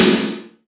SNARE 083.wav